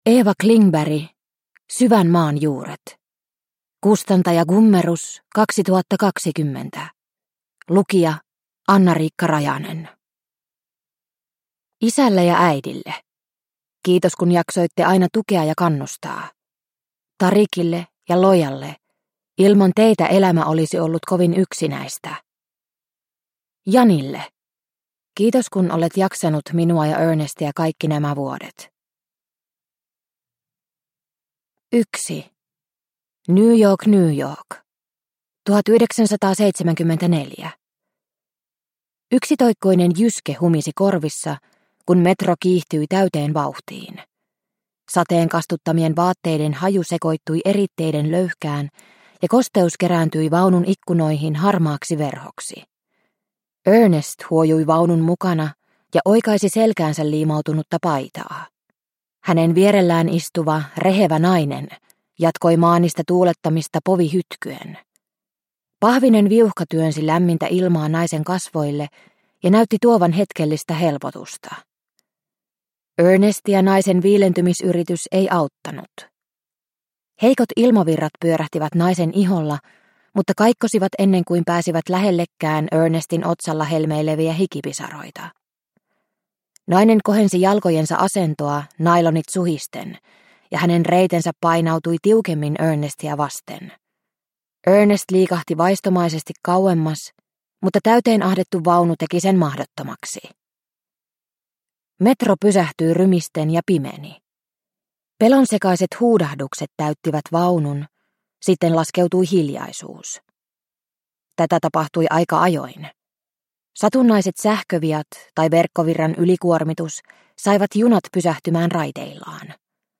Syvän maan juuret – Ljudbok – Laddas ner